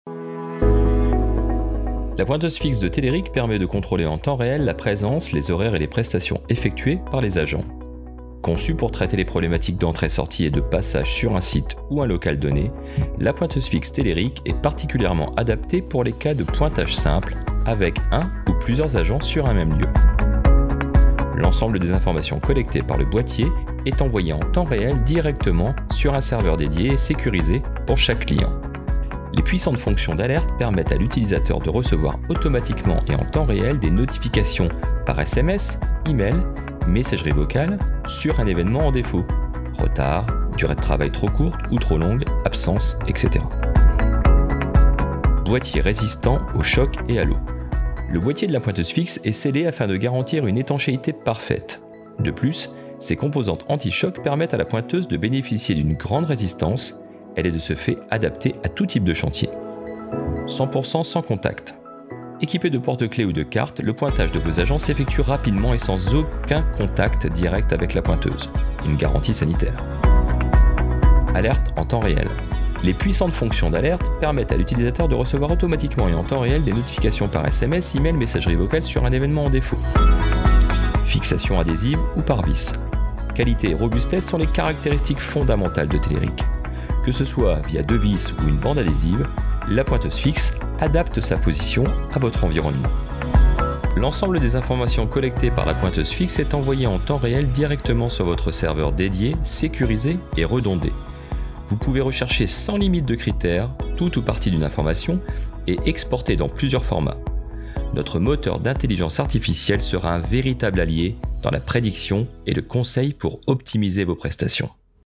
VOIX-OFF-POINTEUSE-FIXE.mp3